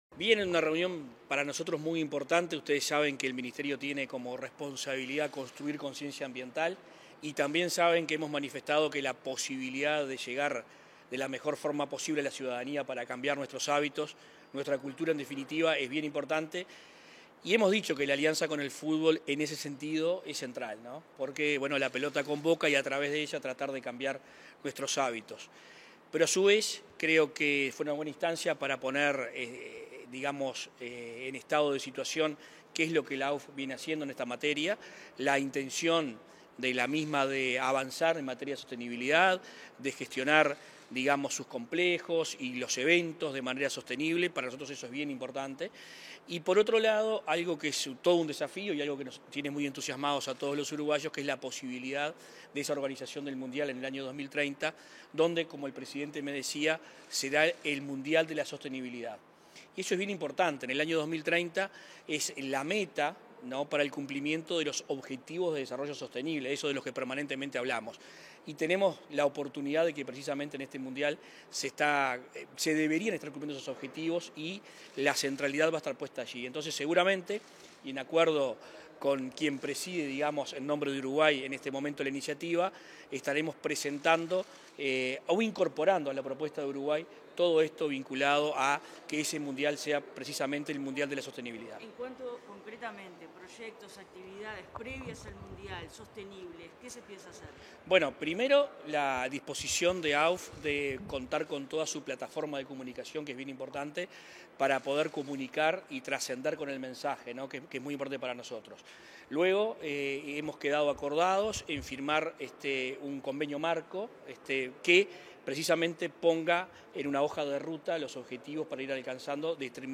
Declaraciones a la prensa del ministro de Ambiente